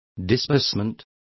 Complete with pronunciation of the translation of disbursement.